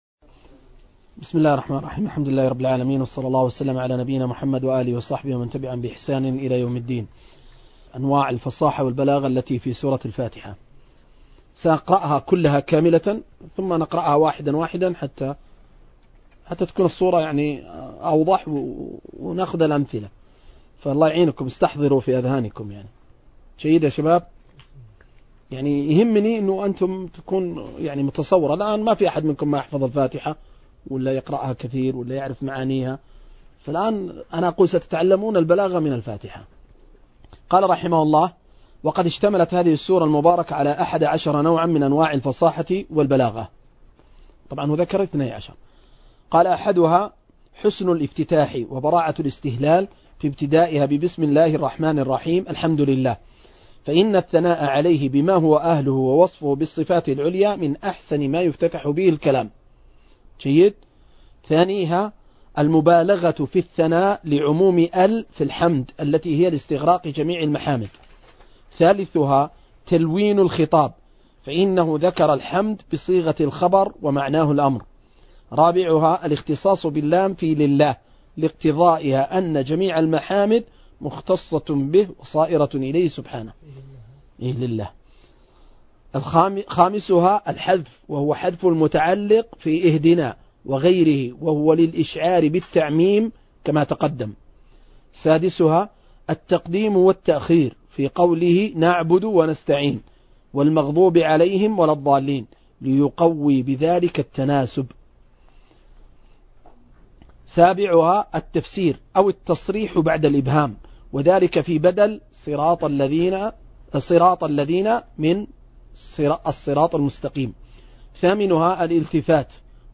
مجلس في شرح أنواع البلاغة في سورة الفاتحة من تفسير العلامة الدوسري رحمه الله